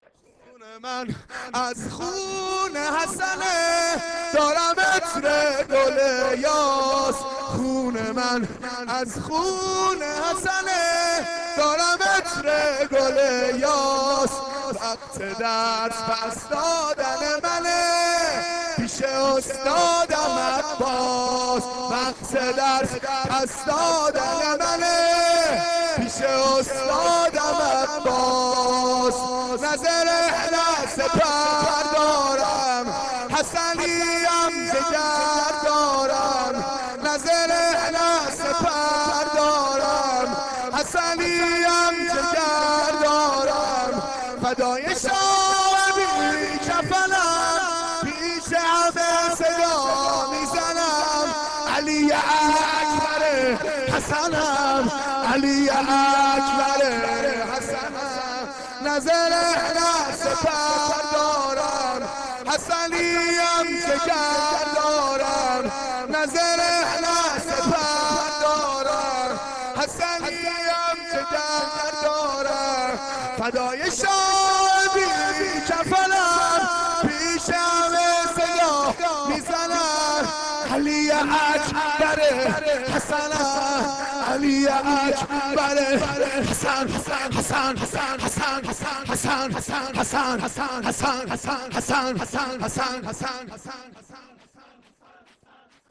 شور شب ششم